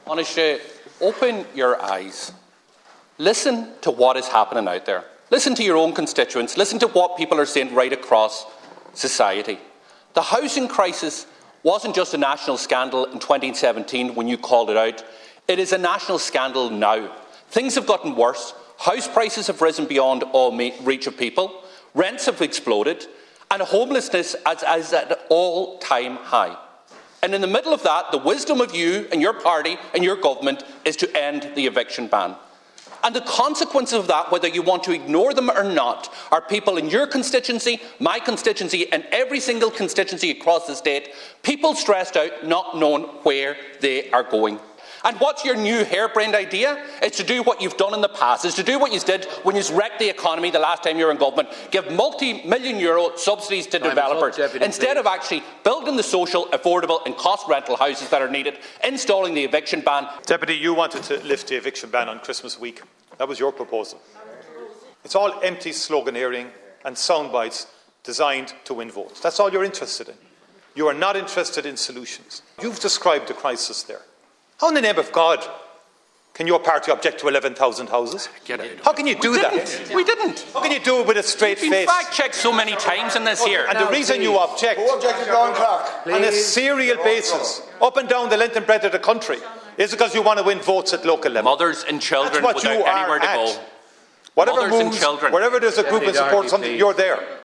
In response, Micheal Martin accused Deputy Doherty of raising issues for political point scoring: